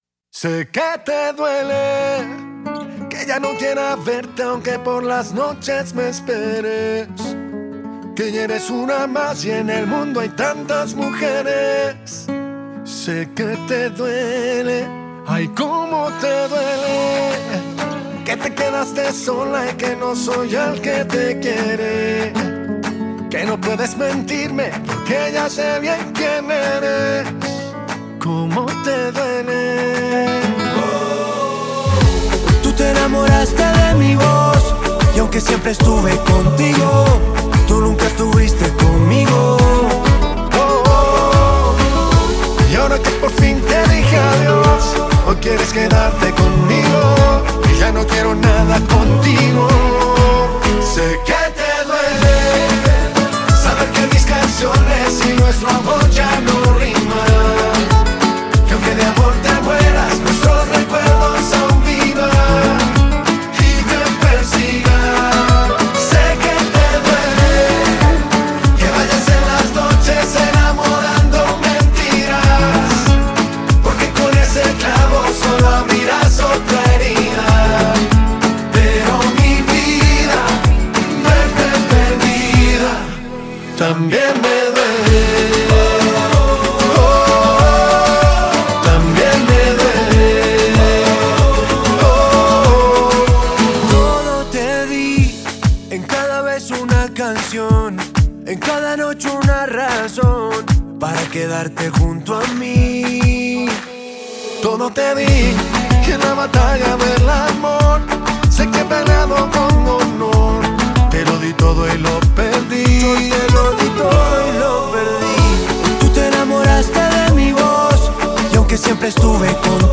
Малость песен решил добавить) Две поп- латиносовские темы))